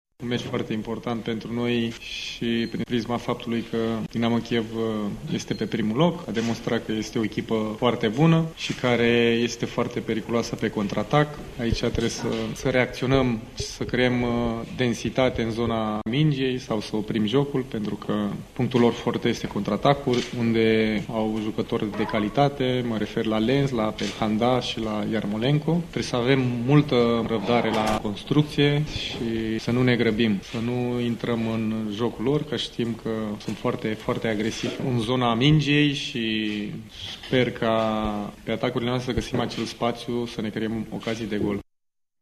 Antrenorul Stelei, Costel Galca: